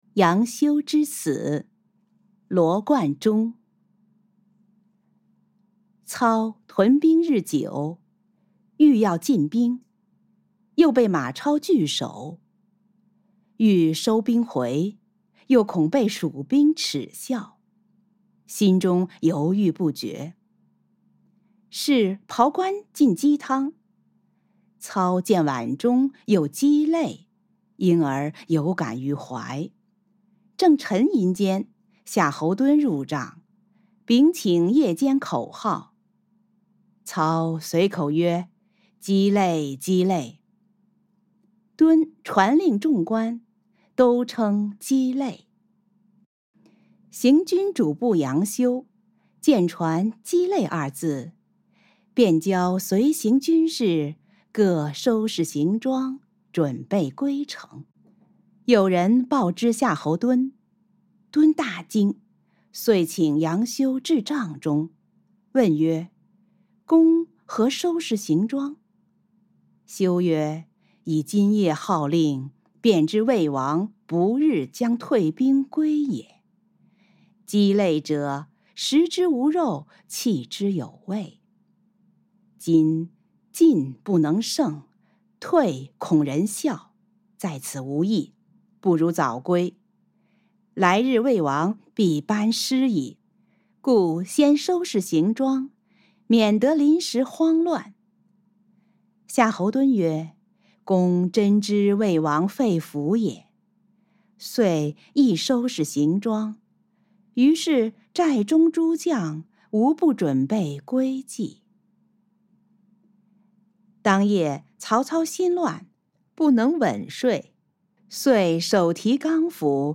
誦讀錄音